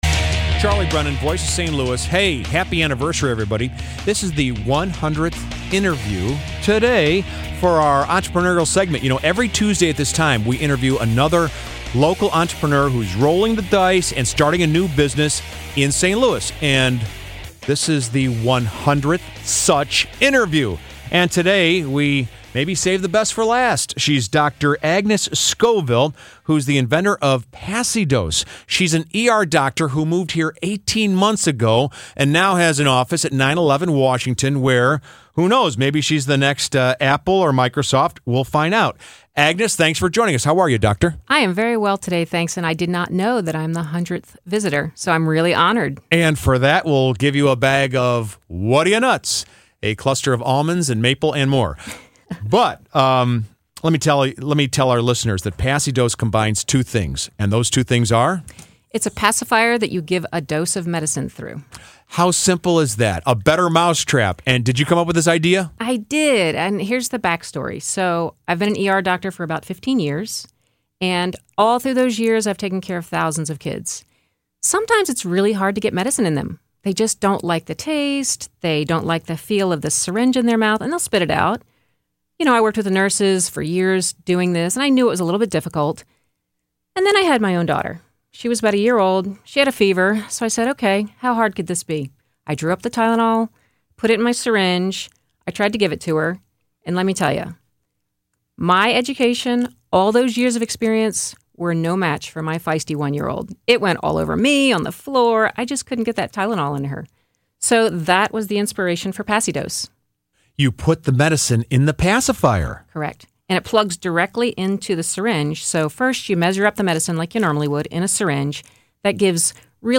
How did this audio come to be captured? on KMOX Radio/St. Louis